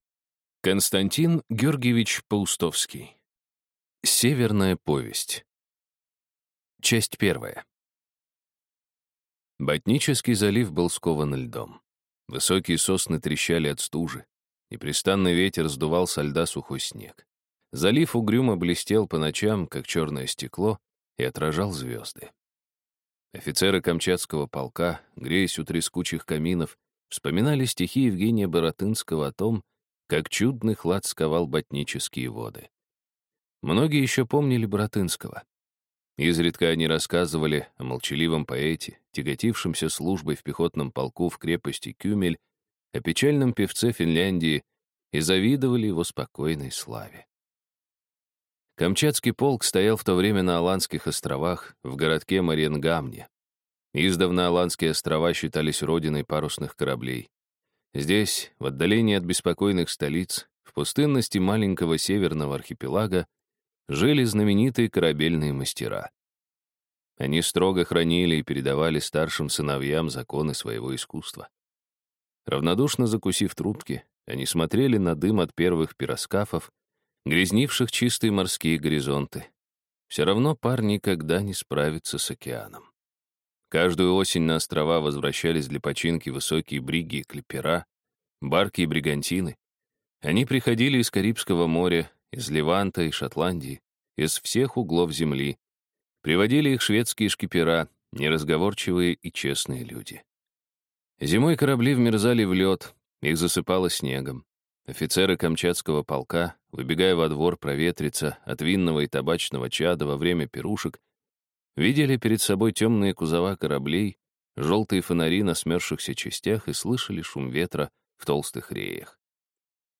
Аудиокнига Северная повесть | Библиотека аудиокниг